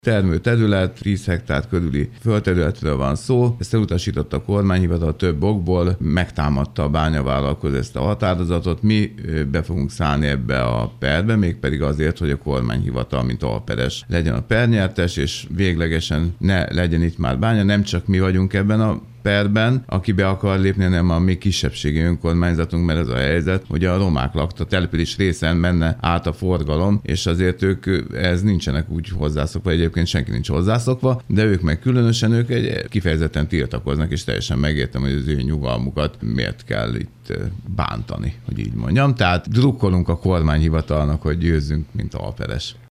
virológust hallják.